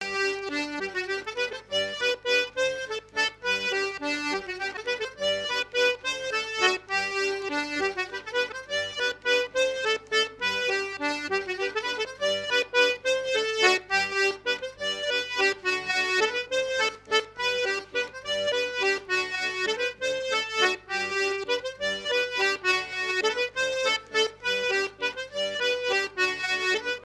danse : bourree
Fête de l'accordéon
Pièce musicale inédite